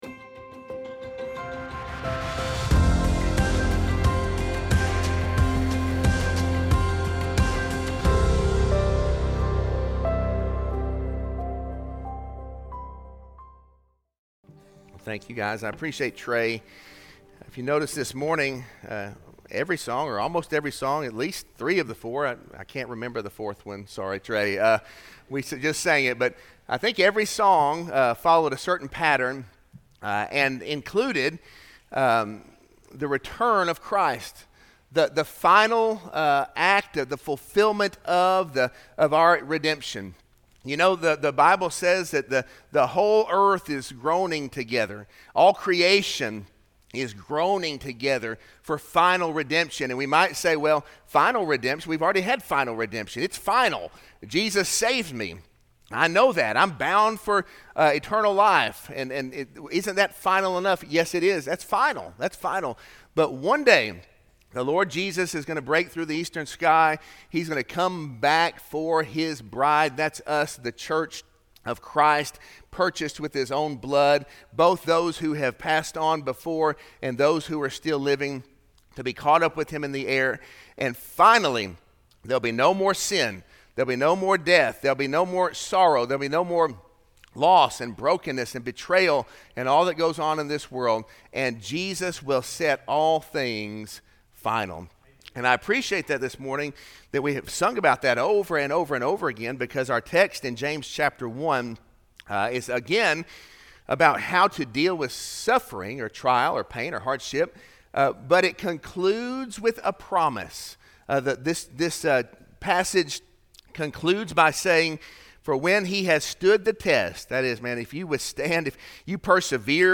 Sermon-6-29-25-audio-from-video.mp3